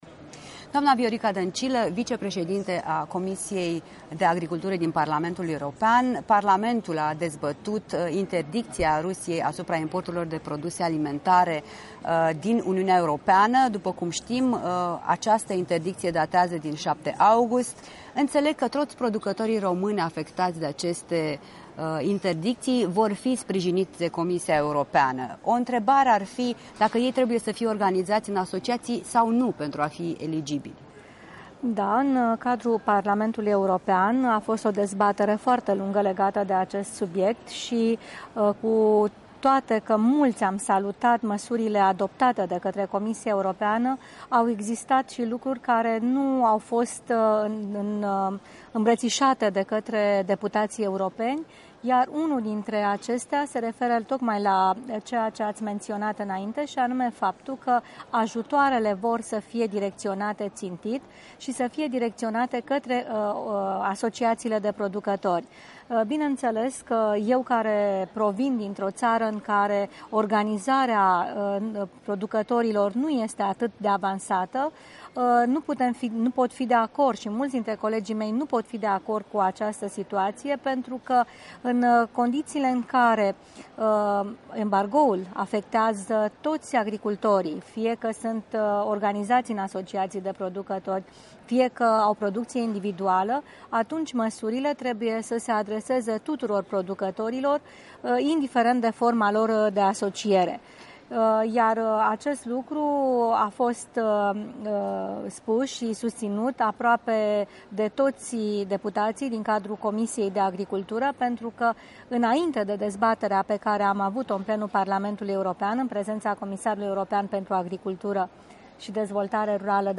În direct de la Strasbourg: o discuție cu europarlamentara Viorica Dancilă